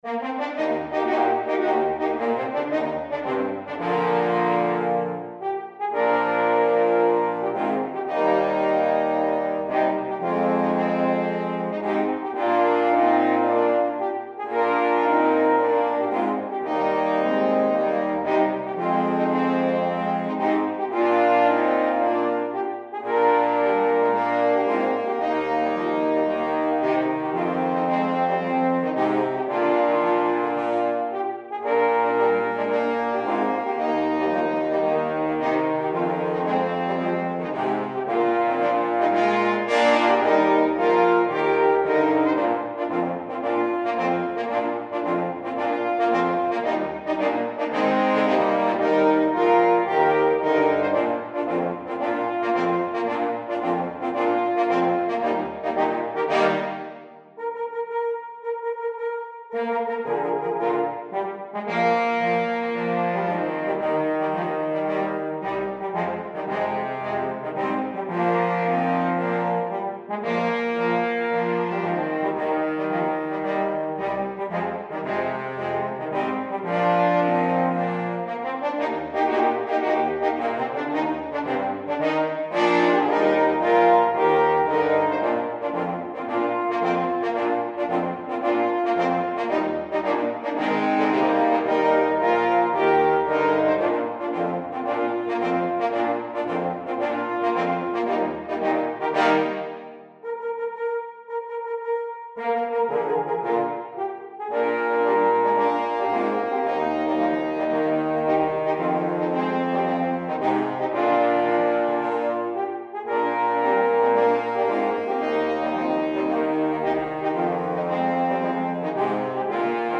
für Hornquartett